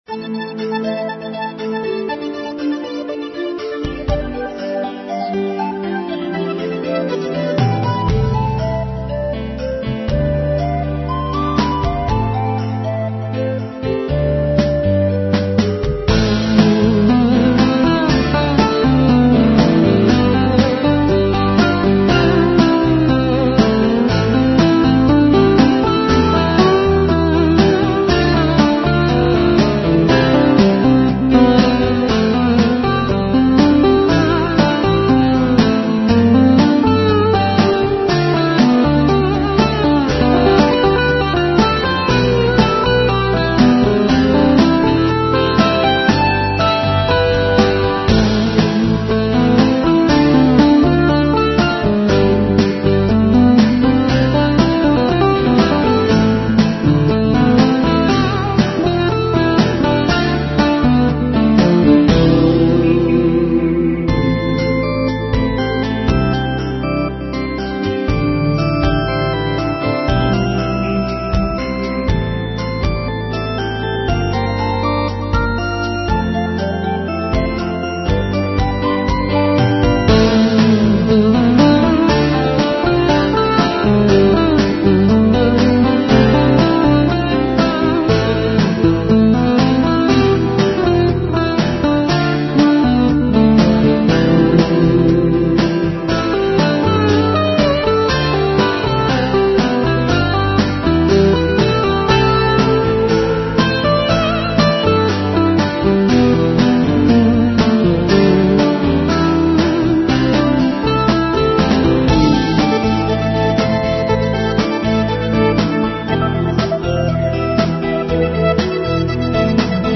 Instrumental Rock Ballad with Melancholic feel